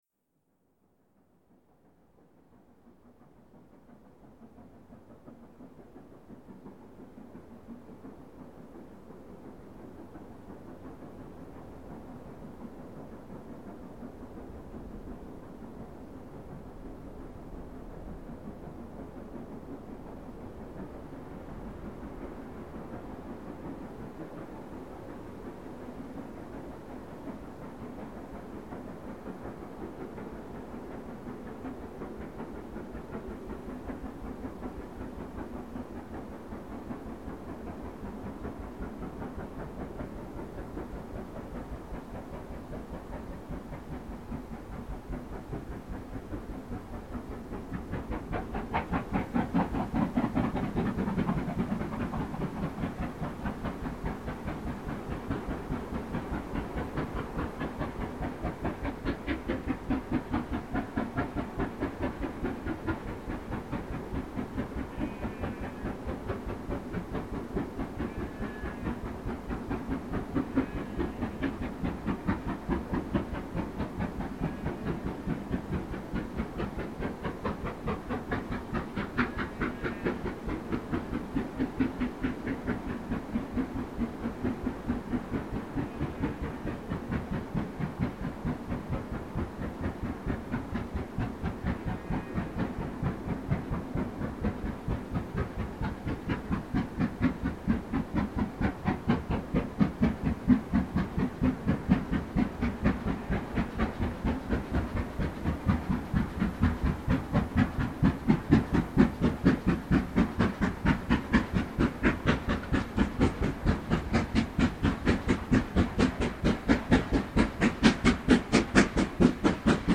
44767 sehr laut bei Green End, 02.08.2000
An der akustisch interessantesten Stelle der Bergfahrt nach Goathland, nämlich nahe der Brücke bei Green End „knallt“ geradezu 44767 am 02.08.2000 um 16:57h in einer phantastischen Lautstärke vorbei - das akustische „Schlüsselerlebnis“ zu dieser Baureihe.
44767 mit Zug nach Pickering, aufgenommen direkt hinter der Feldweg-Brücke bei Green End, um 16:57h am 02.08.2000.   Hier anhören: